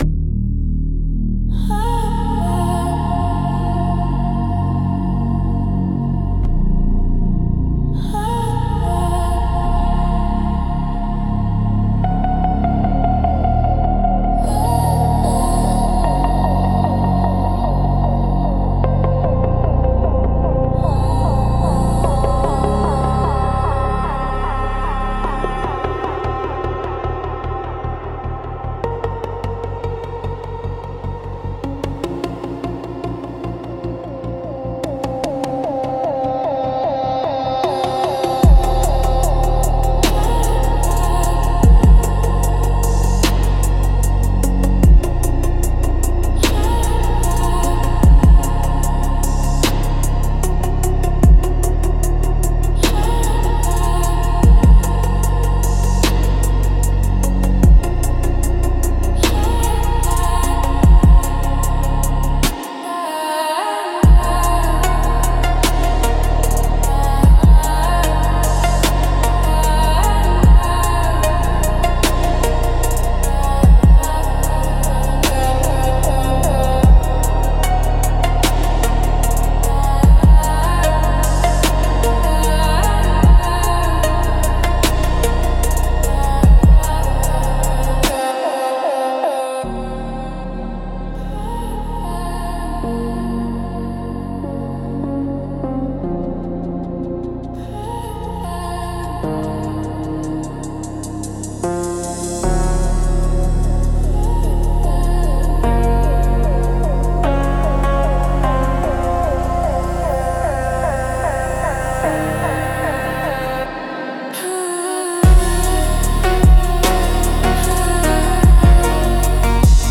Instrumental - Weightless (But Anchored) 2.59